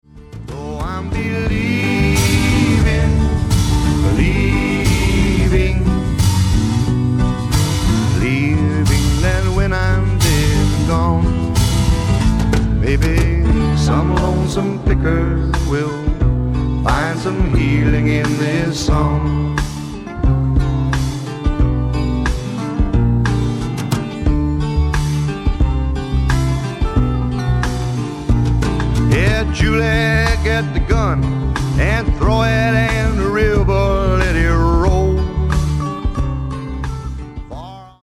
SSW / SWAMP ROCK